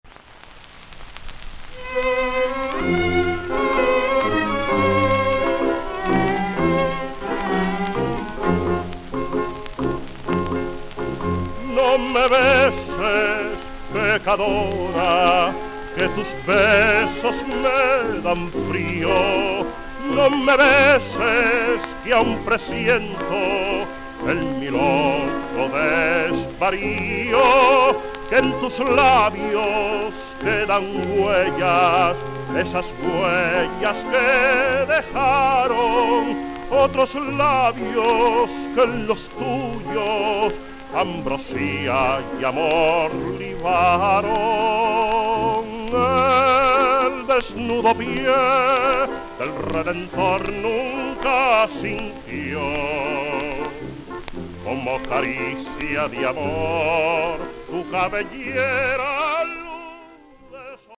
【10inch SP盤78回転】
CONDITIONVG(-) (B面中盤に5秒間ノイズ有) (VINYL)